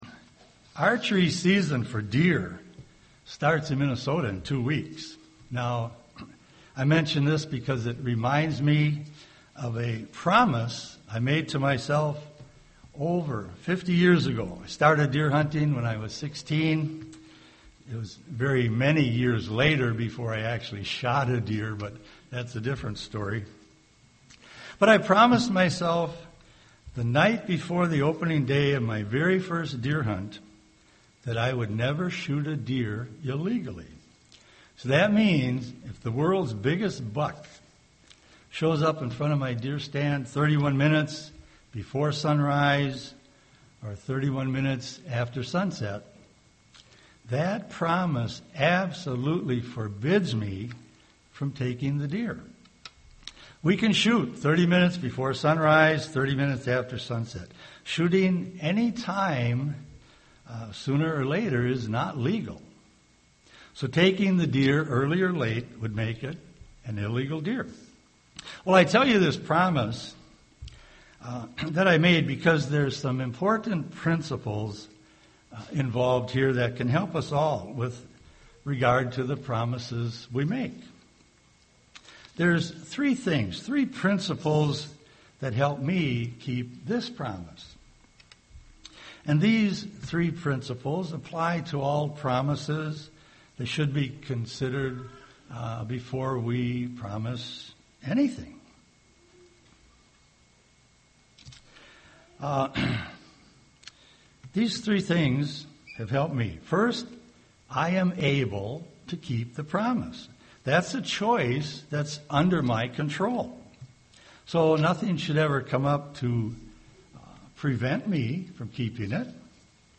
UCG Sermon promises Studying the bible?